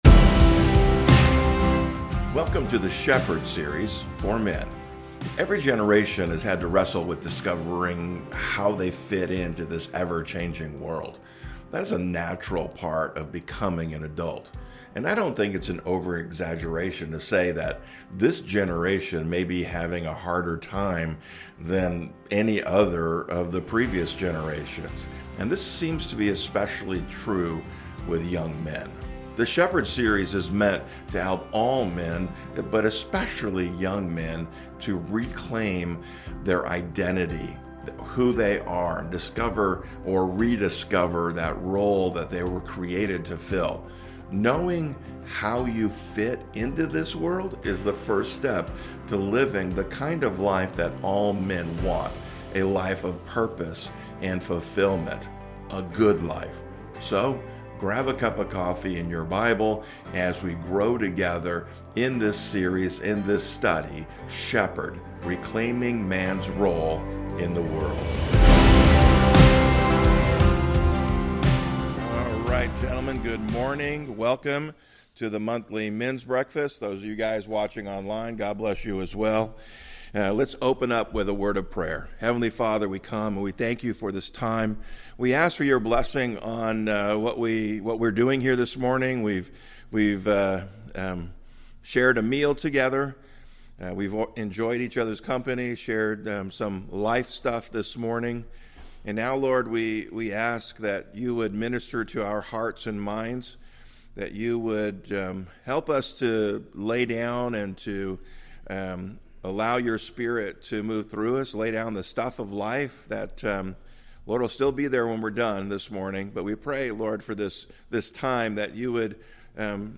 SHEPHERD is a series of messages for men.